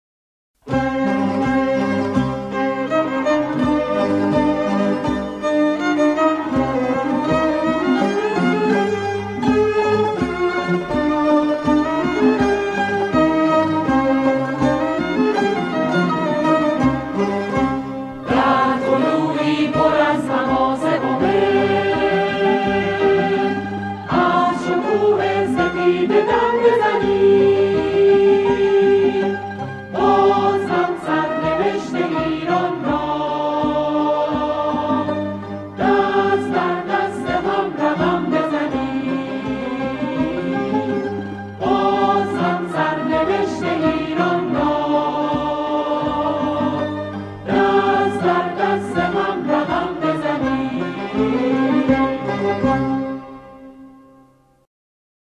سرود کوتاه